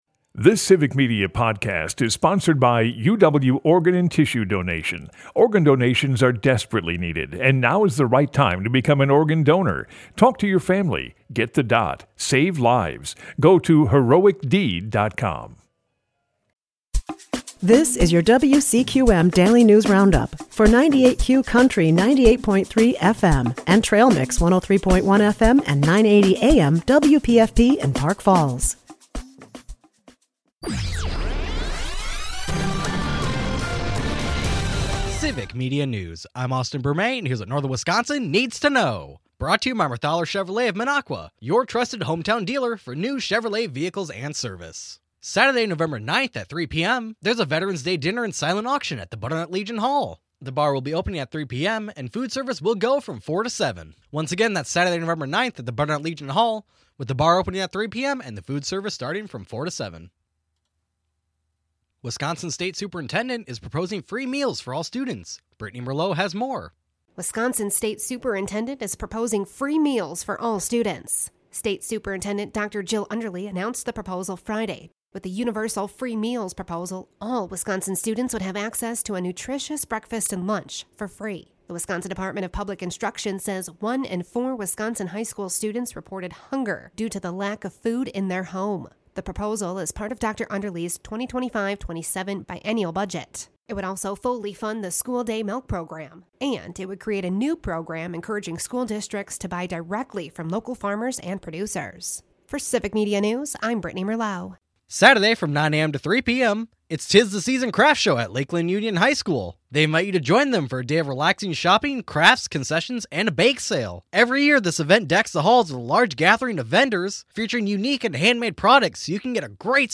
wcqm news